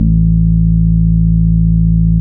Mood Bass (JW3).wav